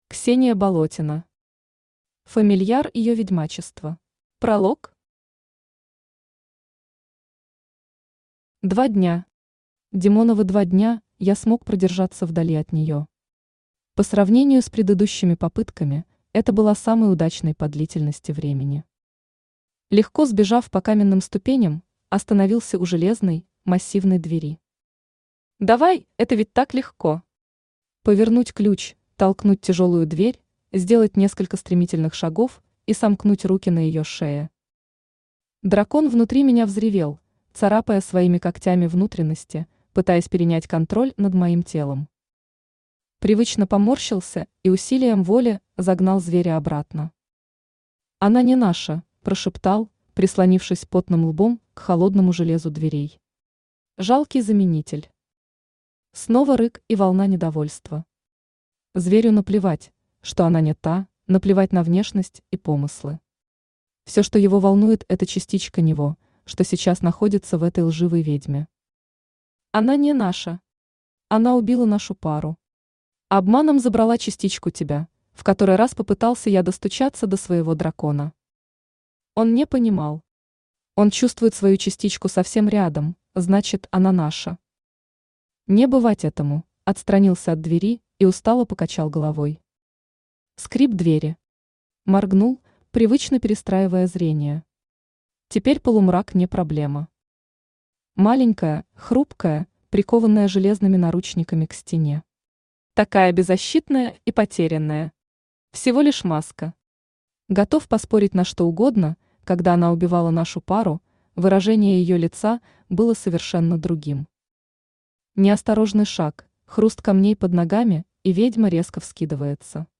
Аудиокнига Фамильяр ее ведьмачества | Библиотека аудиокниг
Aудиокнига Фамильяр ее ведьмачества Автор Ксения Болотина Читает аудиокнигу Авточтец ЛитРес.